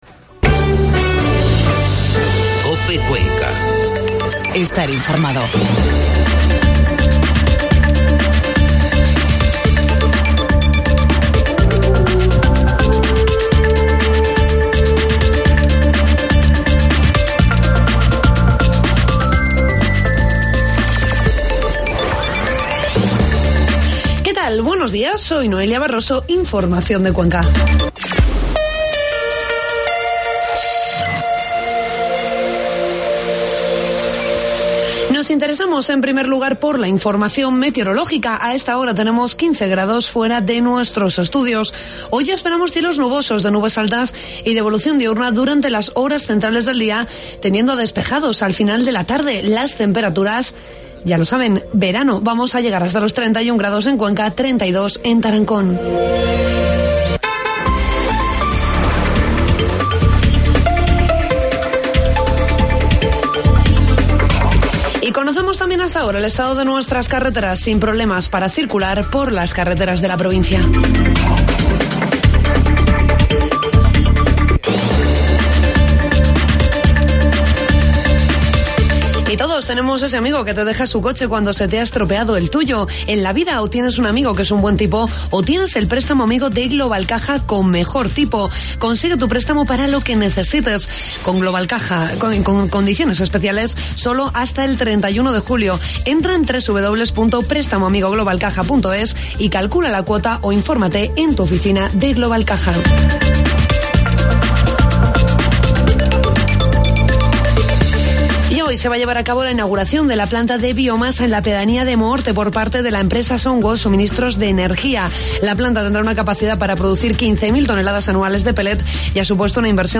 AUDIO: Informativo matinal 18 de junio.